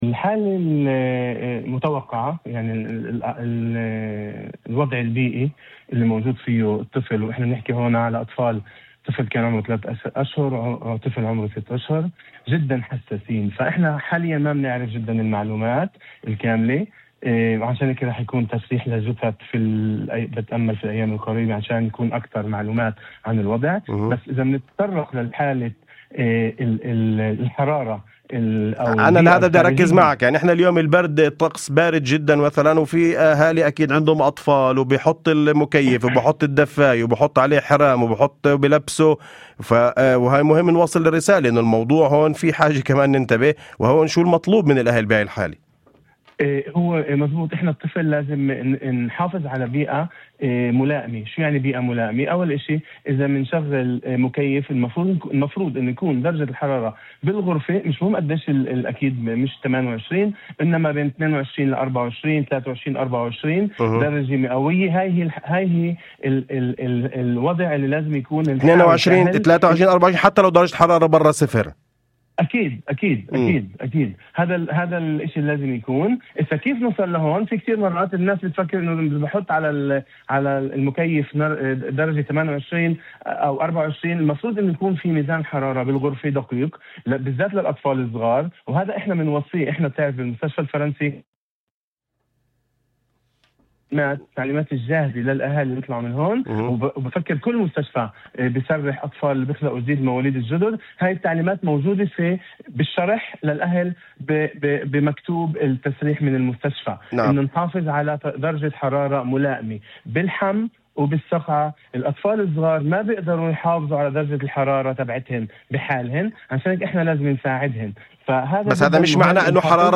في مداخلة ضمن برنامج "أول خبر" على إذاعة الشمس